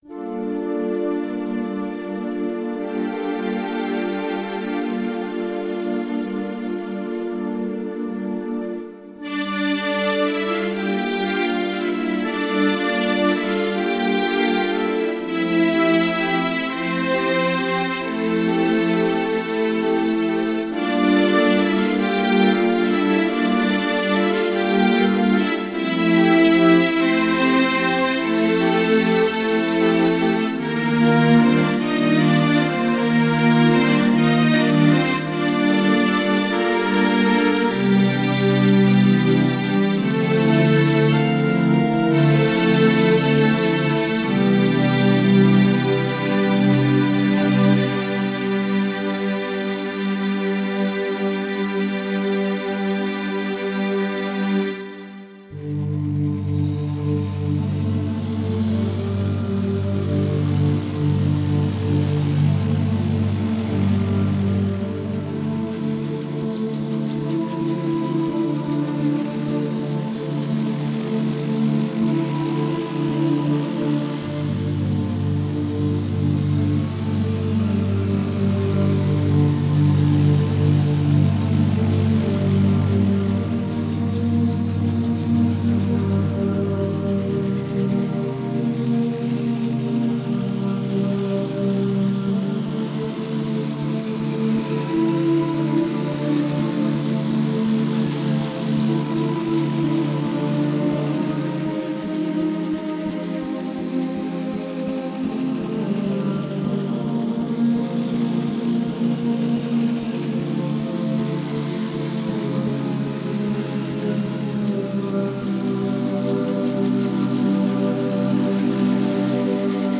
Духовная музыка Медитативная музыка